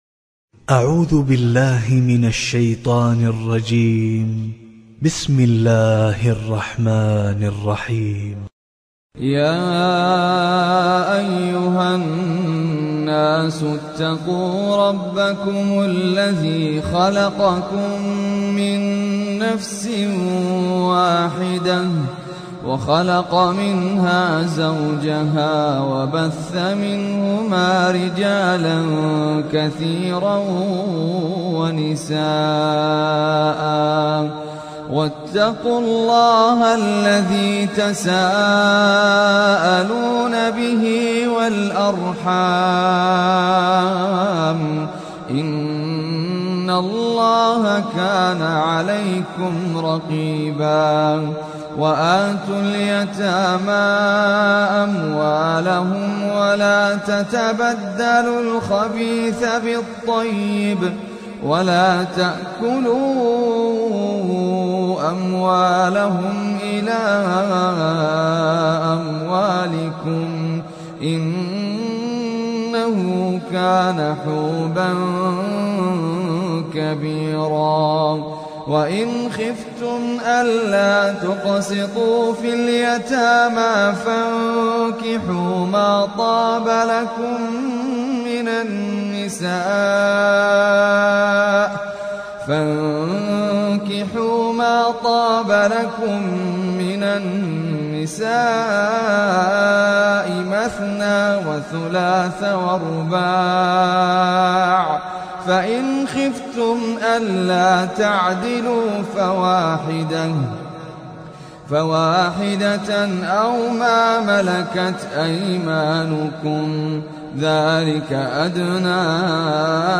Surah An Nisa tilawat MP3